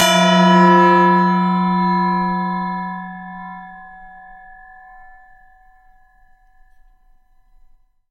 复读铃
描述：这个复读钟是为了听起来像西藏寺院或乡村教堂的钟声，或者是古老村庄的警示钟。时间是不均匀的。它是通过敲打金属板制成的。
标签： 教堂 叮当 金属制品 寺院 monastry 振铃 寺院 托盘
声道立体声